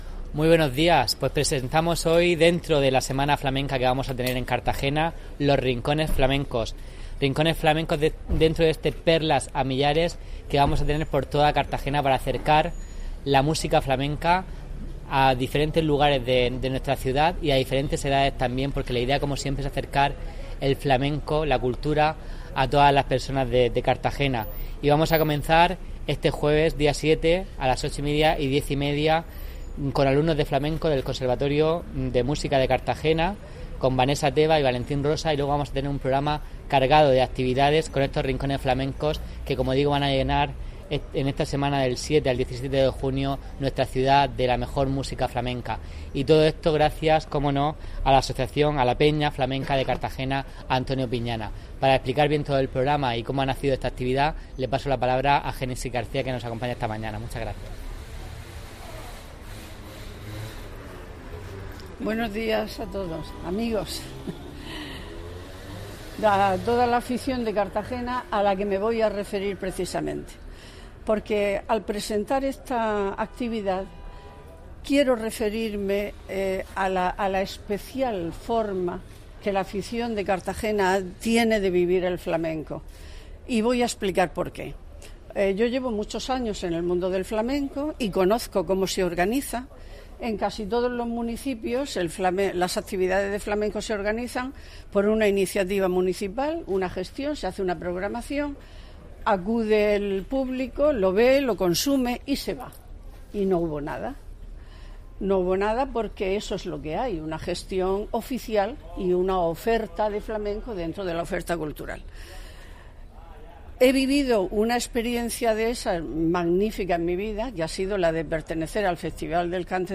La Posada Jamaica, ha sido el lugar escogido para presentar este amplio programa de actuaciones.